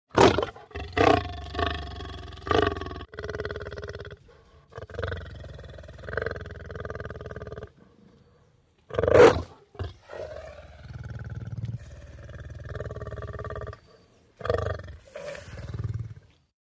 ANIMALS WILD: Male lion snarling & growling.